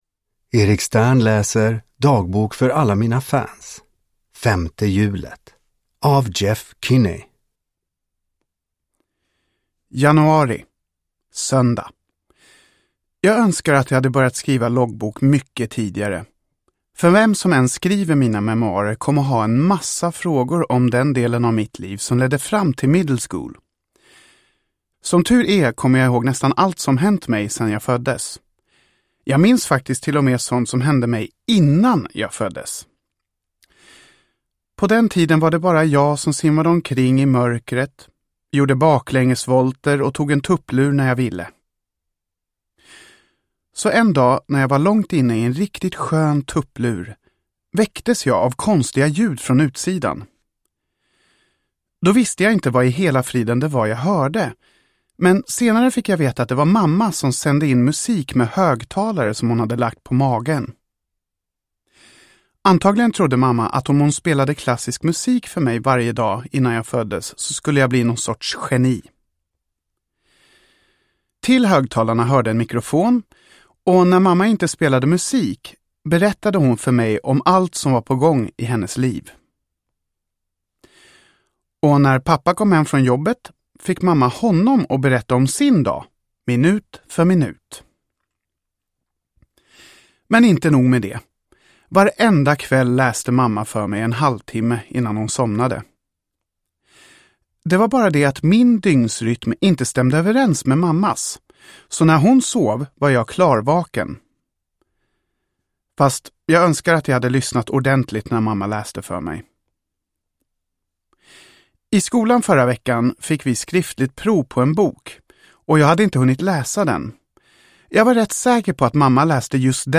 Femte hjulet – Ljudbok – Laddas ner